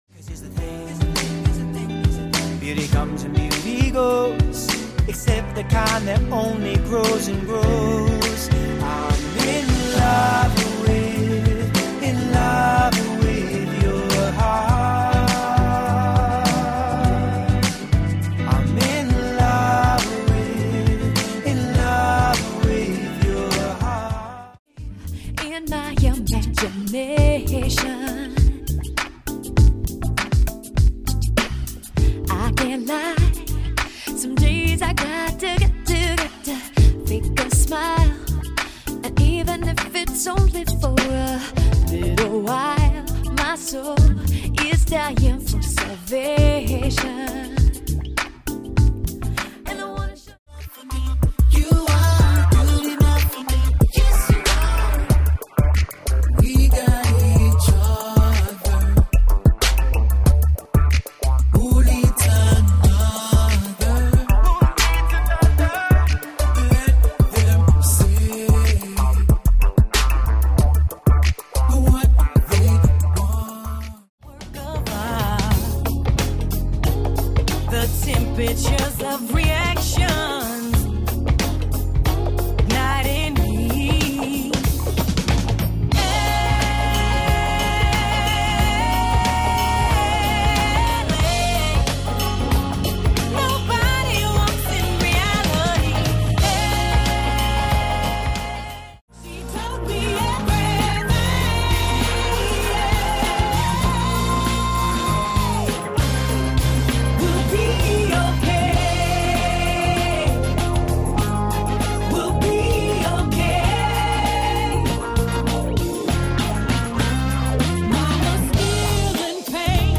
styl - pop/rhythm/r'n'b